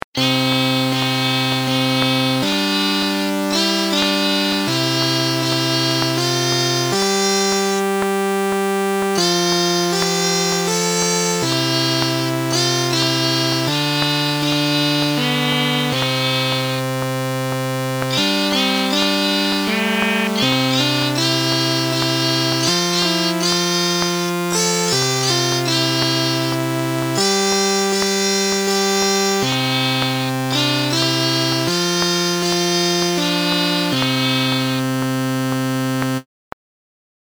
音MAD